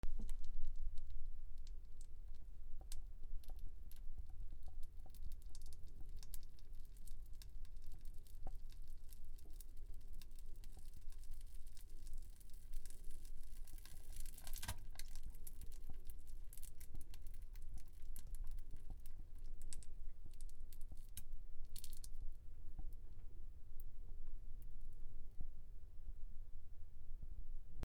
/ J｜フォーリー(布ずれ・動作) / J-25 ｜おなら・大便